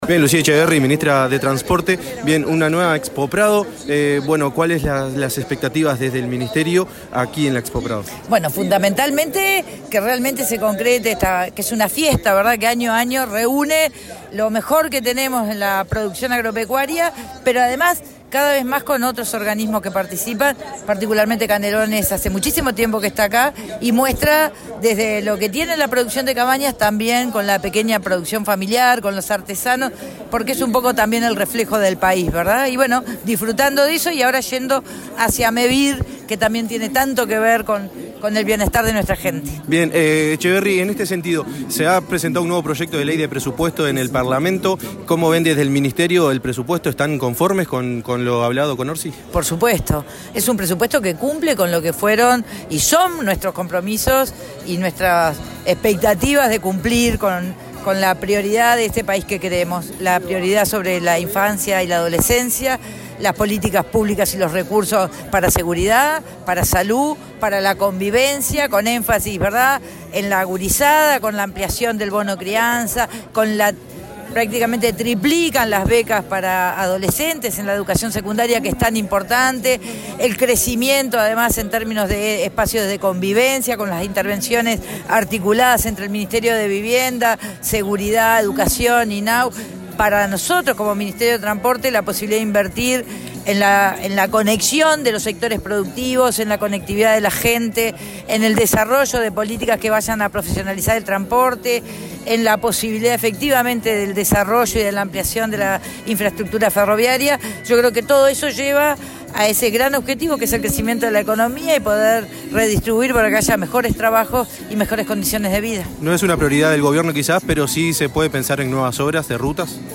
La ministra de Transporte y Obras Públicas, Lucía Etcheverry en diálogo con 970 Universal en la Expo Prado, explicó las características principales del presupuesto otorgado a la cartera.